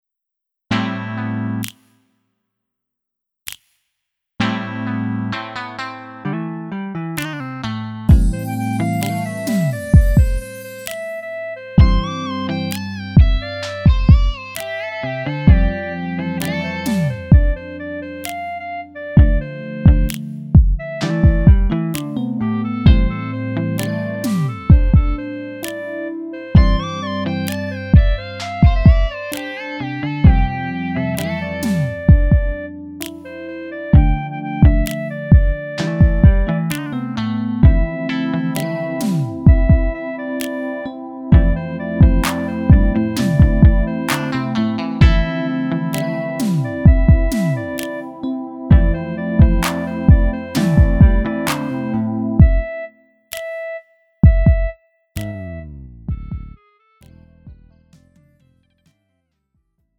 음정 원키 4:01
장르 가요 구분 Lite MR
Lite MR은 저렴한 가격에 간단한 연습이나 취미용으로 활용할 수 있는 가벼운 반주입니다.